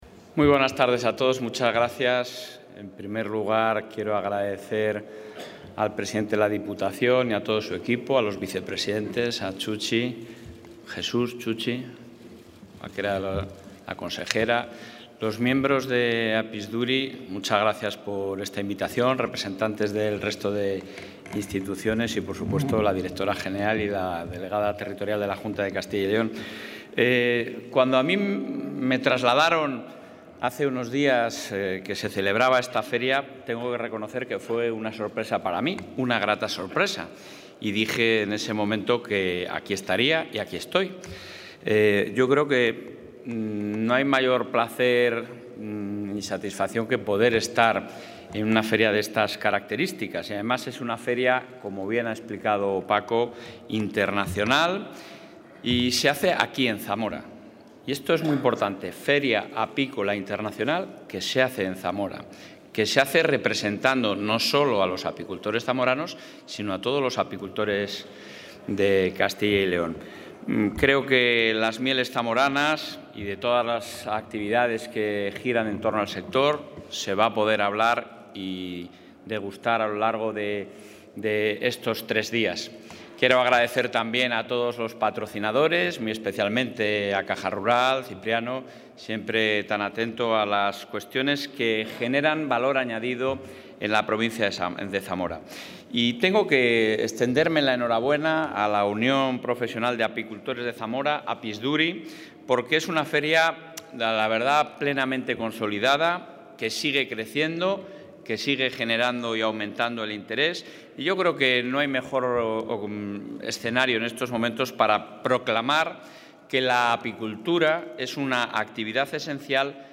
Intervención del presidente de la Junta.
Durante la inauguración de la cuarta Feria Apícola Internacional de Zamora, «Meliza», Fernández Mañueco ha destacado que se está trabajando con la Diputación para sacar adelante el reconocimiento de la marca de calidad «Miel de Zamora».